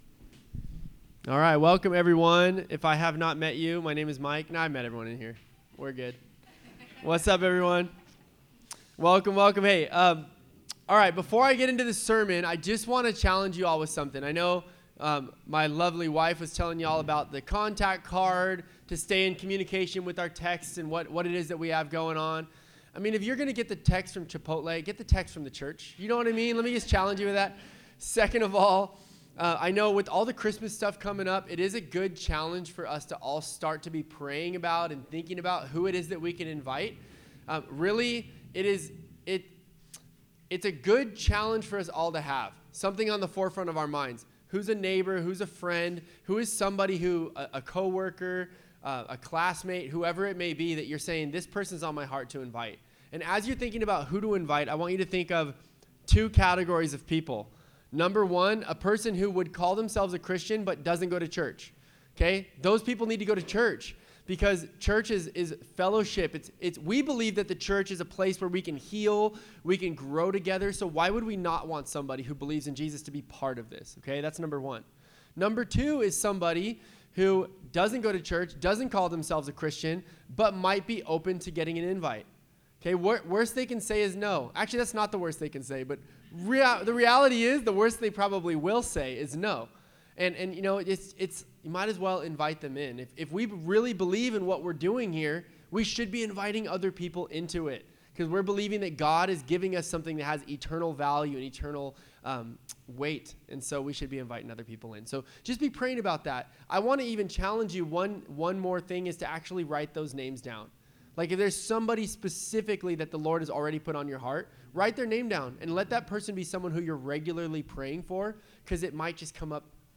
Sermons | Revive Church